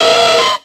Cri de Dynavolt dans Pokémon X et Y.